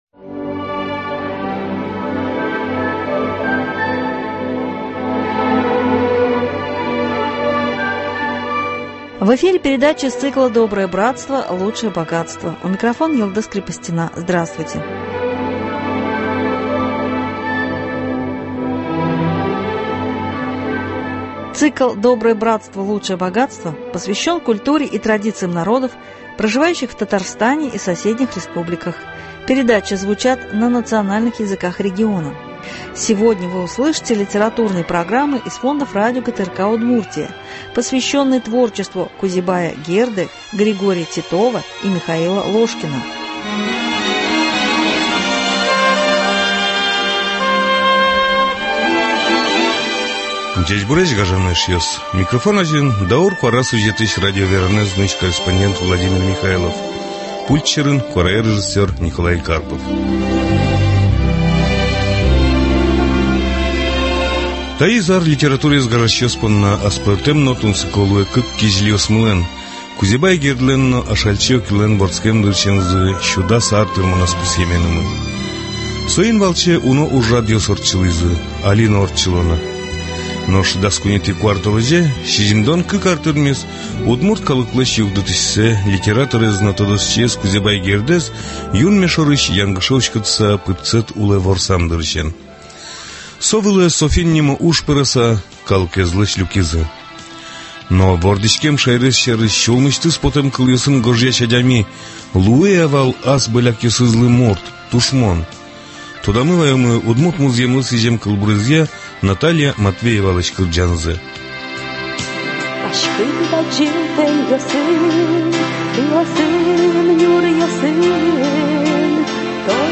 Цикл посвящен культуре и традициям народов, проживающих в Татарстане и соседних республиках, передачи звучат на национальных языках региона . Сегодня вы услышите литературные программы на удмуртском языке из фондов радио ГТРК Удмуртия, посвященные творчеству Кузебая Герды, Григория Титова, Михаила Ложкина.